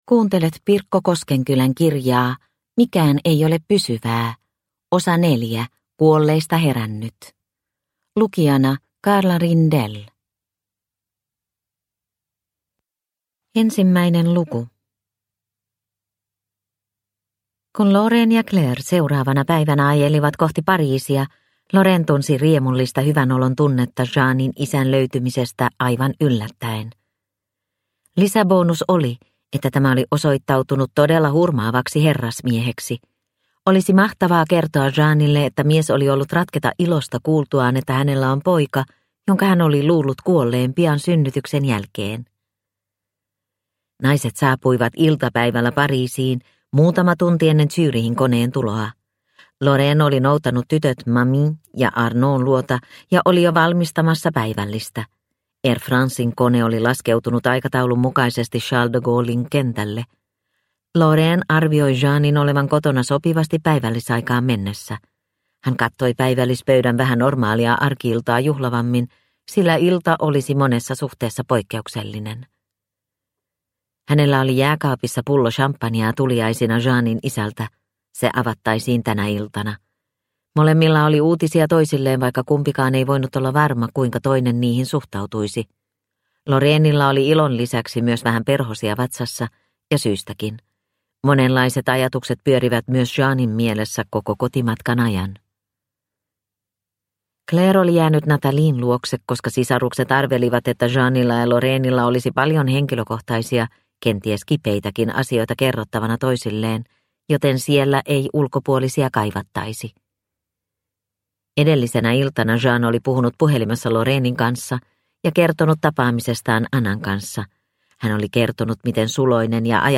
Mikään ei ole pysyvää - Osa 4 Kuolleista herännyt – Ljudbok – Laddas ner